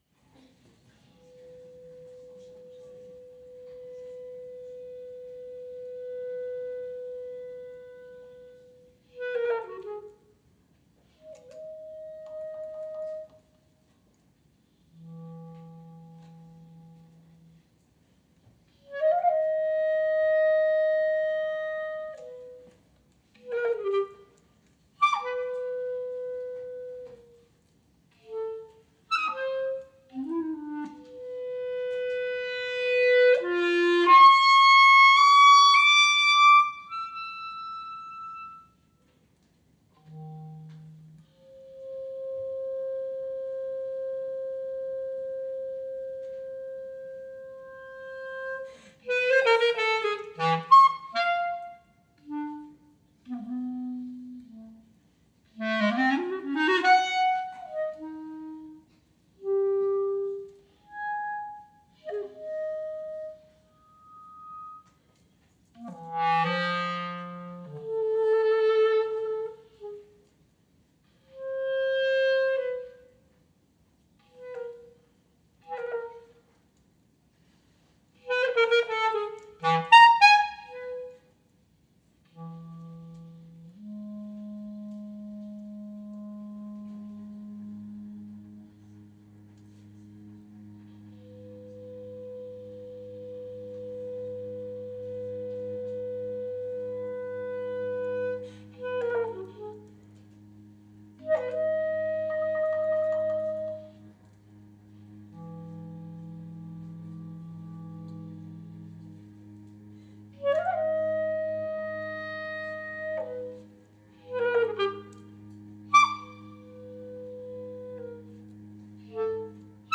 clarinet
cello pedal tone